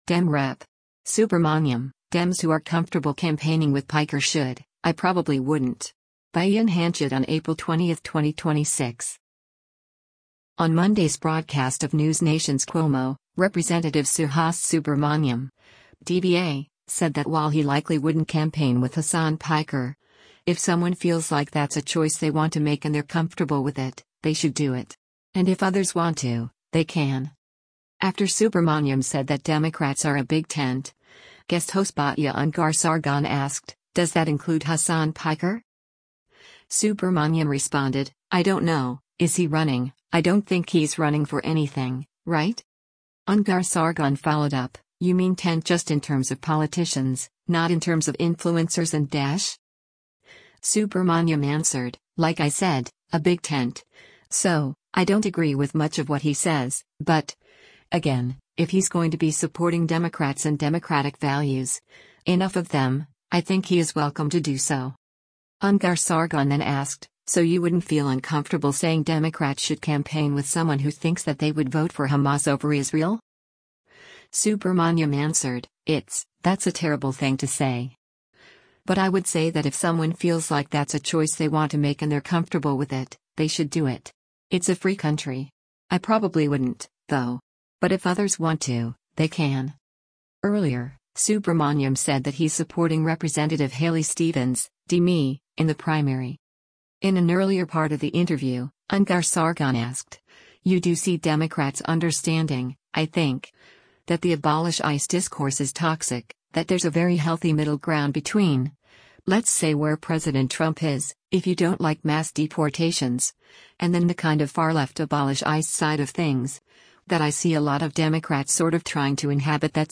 On Monday’s broadcast of NewsNation’s “Cuomo,” Rep. Suhas Subramanyam (D-VA) said that while he likely wouldn’t campaign with Hasan Piker, “if someone feels like that’s a choice they want to make and they’re comfortable with it, they should do it.” And “if others want to, they can.”
After Subramanyam said that Democrats are a big tent, guest host Batya Ungar-Sargon asked, “Does that include Hasan Piker?”
In an earlier part of the interview, Ungar-Sargon asked, “You do see Democrats understanding, I think, that the abolish ICE discourse is toxic, that there’s a very healthy middle ground between, let’s say where President Trump is, if you don’t like mass deportations, and then the kind of far left abolish ICE side of things, that I see a lot of Democrats sort of trying to inhabit that space, yourself included, we’ve talked about this at great length.